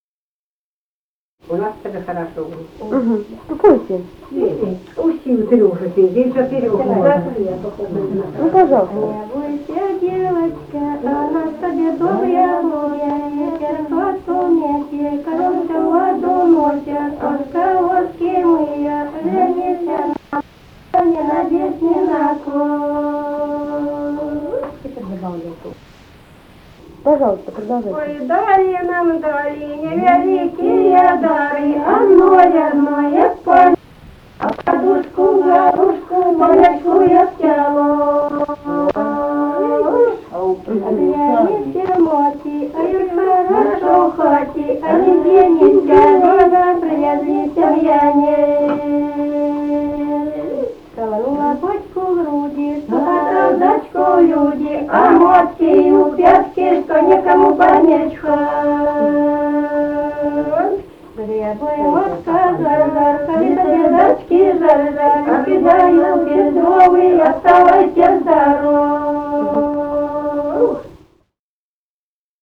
Музыкальный фольклор Климовского района 046. «Не бойся, девочка» (свадебная).
Записали участники экспедиции